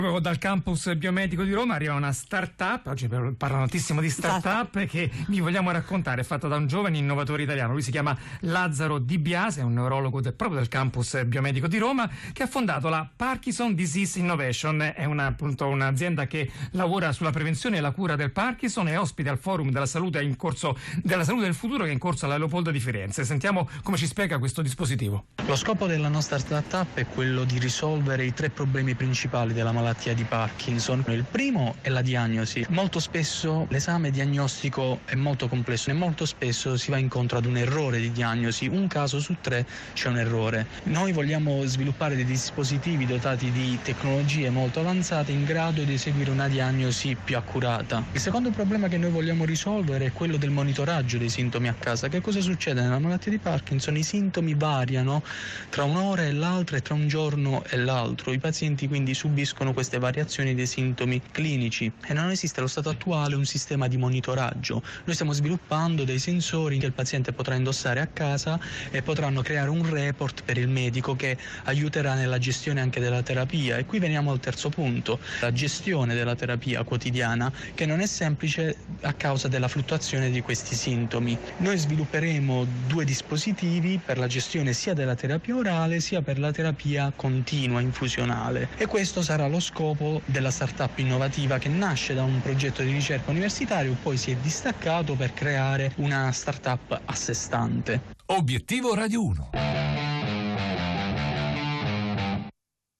Listen to the interview with Brain Innovations on Radio RAI.